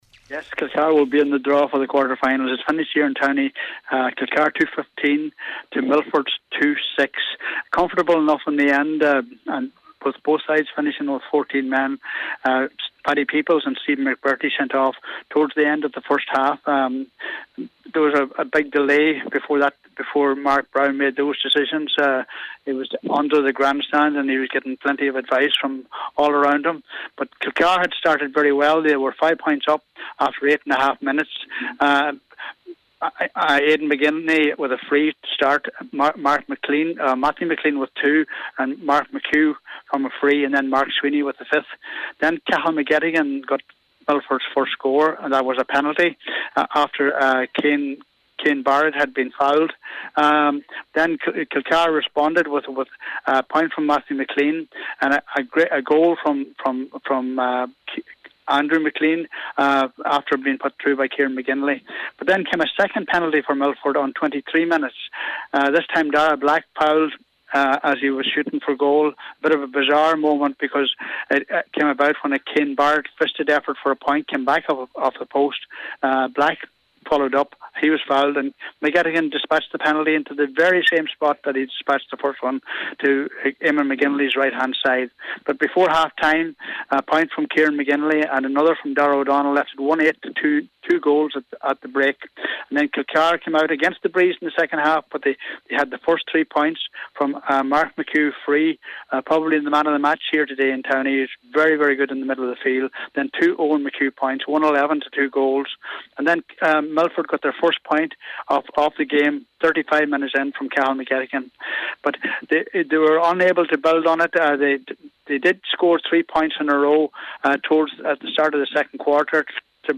in Towney for Highland Radio…